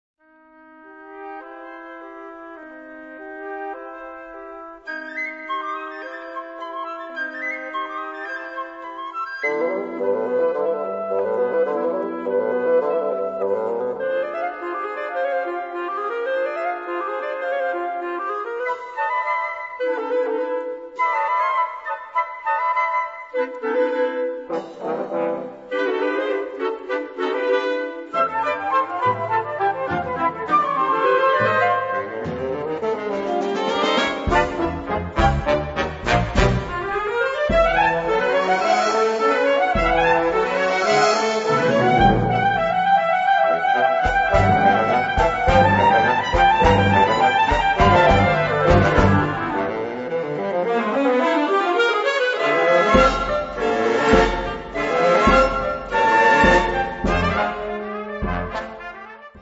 Kategorie Blasorchester/HaFaBra
Unterkategorie Suite
Besetzung Ha (Blasorchester)